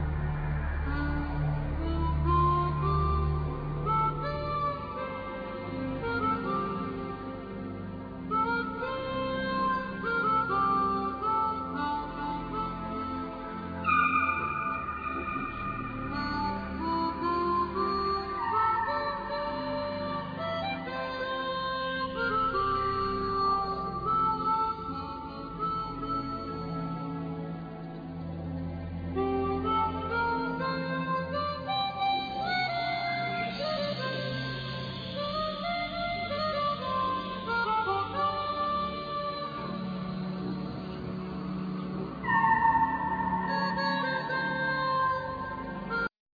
Guitar,Harmonica,Programing,Guitar Synthe
Drums
Bandneon
Vocal
Bass
Keyboards
Piano
DoubleBass
Percussions